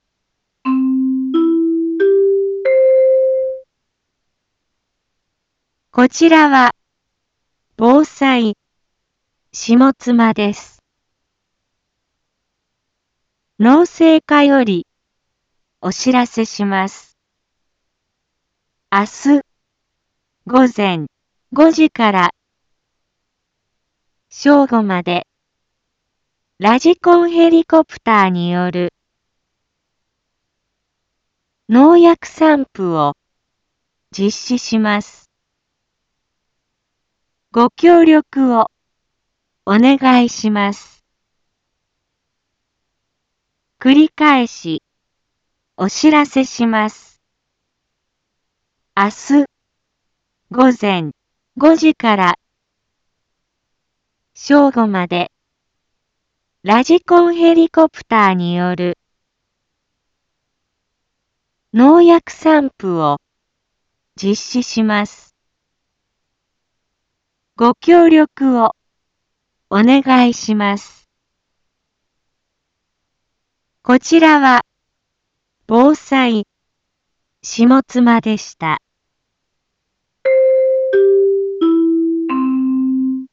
一般放送情報
Back Home 一般放送情報 音声放送 再生 一般放送情報 登録日時：2021-04-24 12:31:27 タイトル：麦のﾗｼﾞｺﾝﾍﾘによる防除について（豊加美） インフォメーション：こちらは防災下妻です。